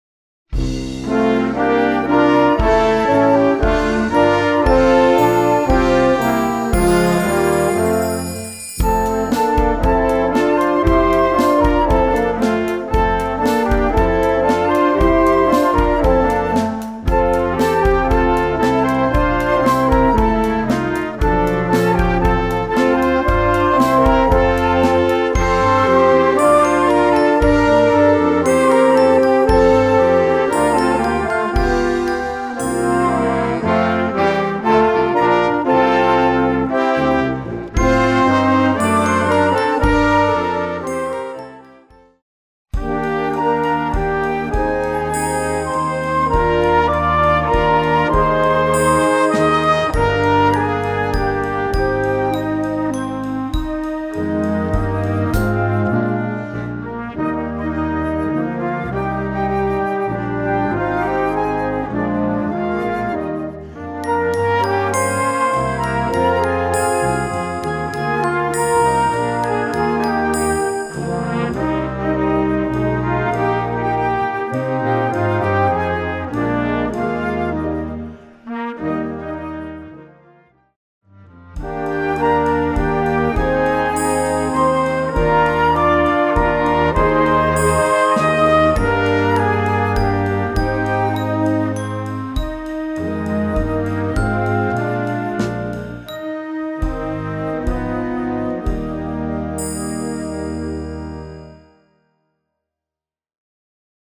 Gattung: Weihnachtsmusik für Jugendblasorchester
Besetzung: Blasorchester